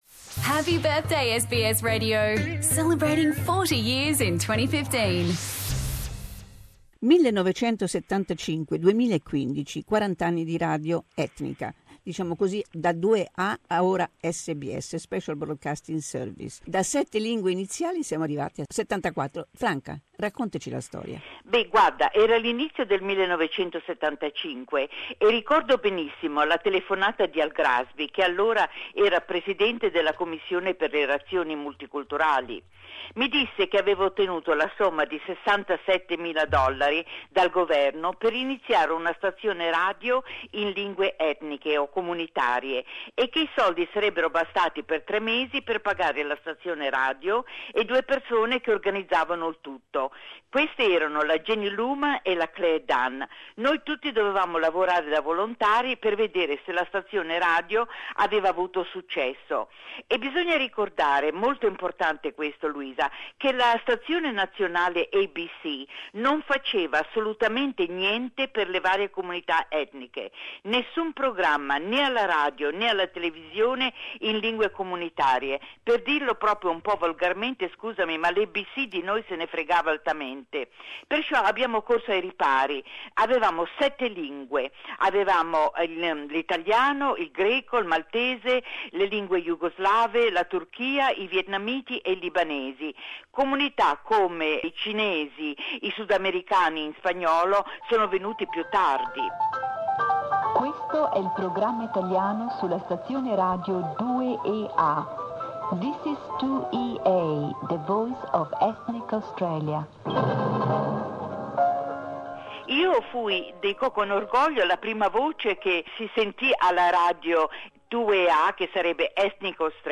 As SBS Radio celebrates 40 years, we asked some of the former broadcasters to share their memories with us. Franca Arena launched the Italian program in 1975, from Sydney, when SBS Radio was still Radio 2EA.